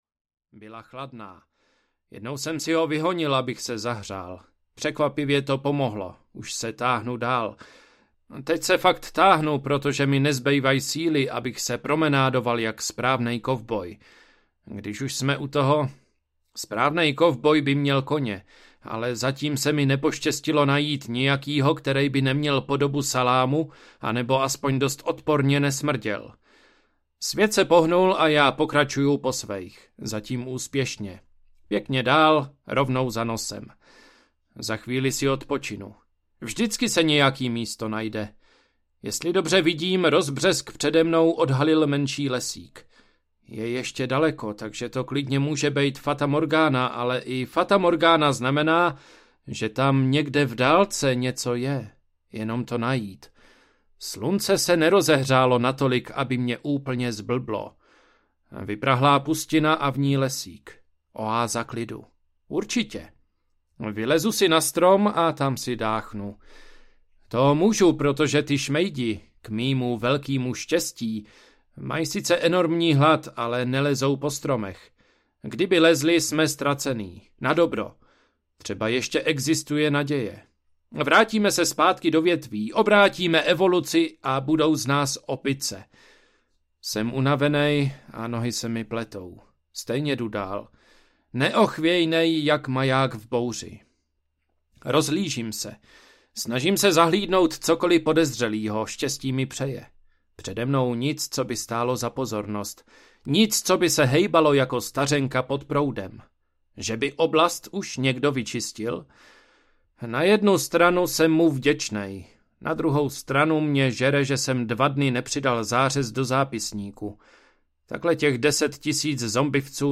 Život v ráji audiokniha
Ukázka z knihy